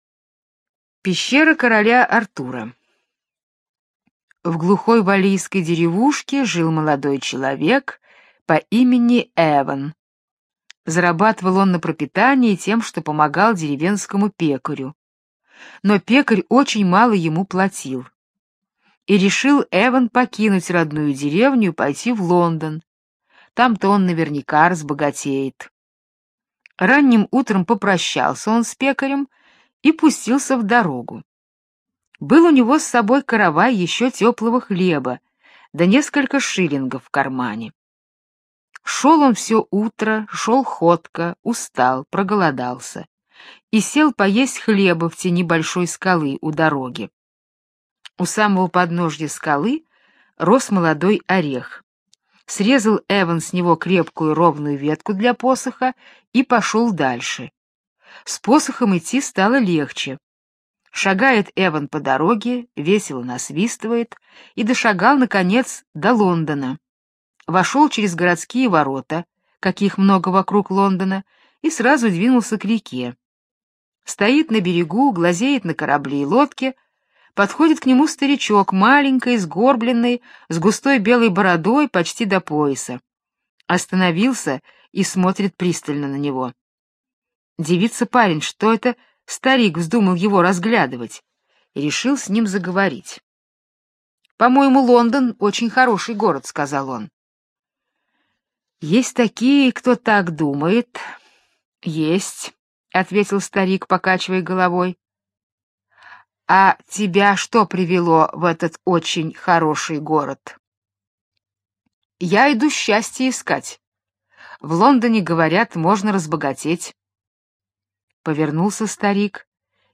Пещера короля Артура - британская аудиосказка - слушать онлайн